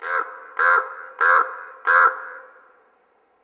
crow_leise.wav